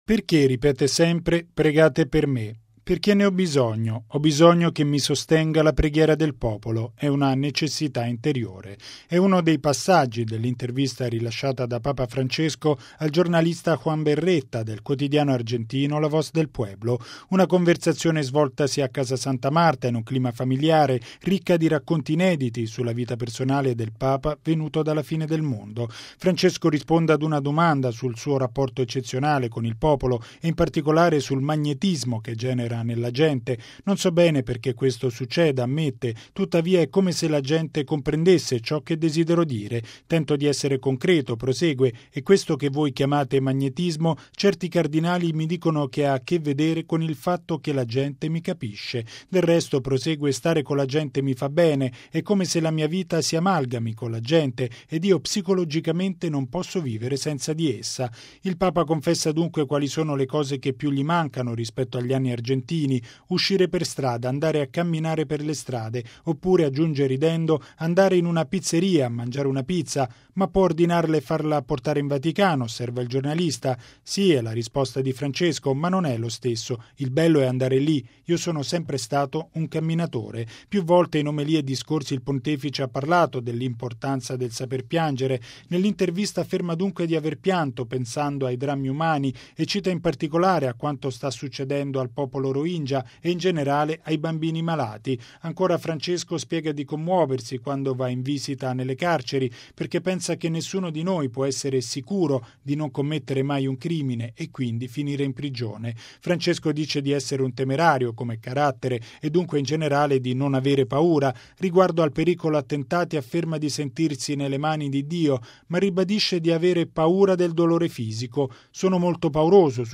Una sintesi della conversazione di Papa Francesco con “La Voz del Pueblo” nel servizio